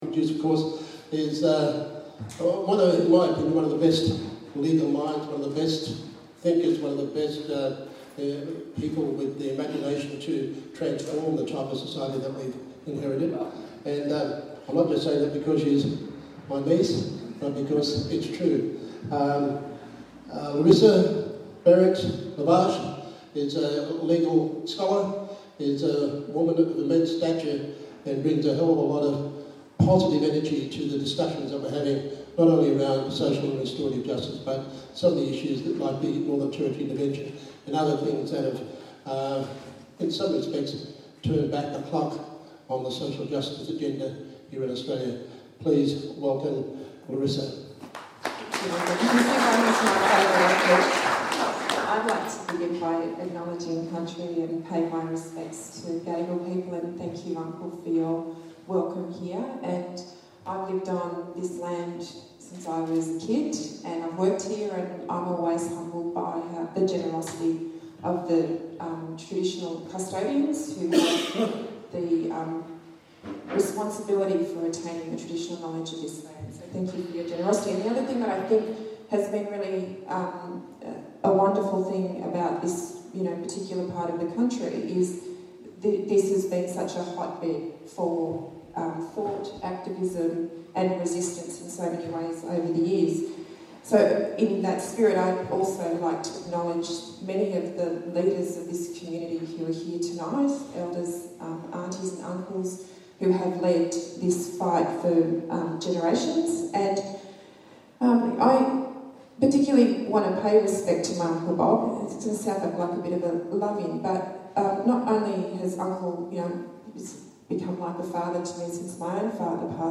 Social Justice conversations at Tranby, Glebe, Sydney - "Have We Surrendered?
Speech by Prof Larissa Behrendt